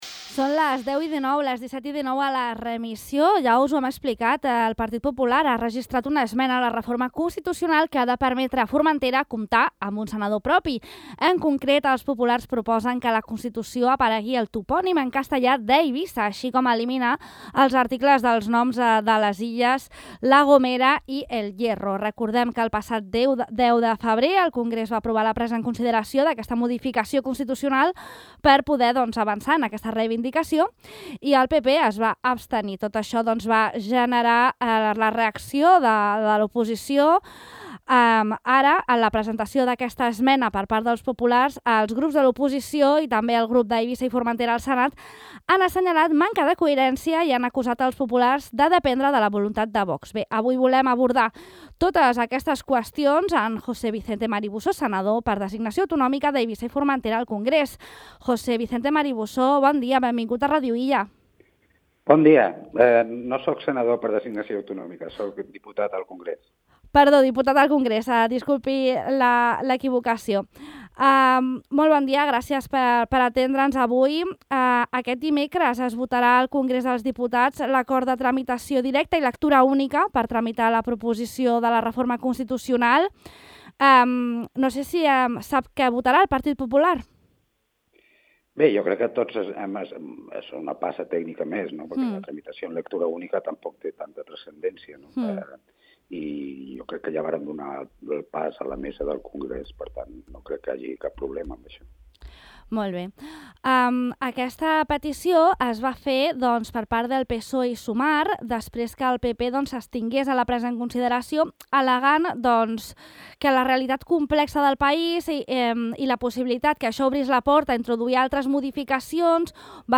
Avui al De Far a Far de Ràdio Illa hem parlat amb José Vicente Marí Bosó, diputat al Congreso de los Diputados del Partido Popular, perquè expliqui el sentit de l’esmena registrada pel seu grup en el procediment de reforma de la Constitución Española de 1978 que permetria que Formentera compti amb un senador propi.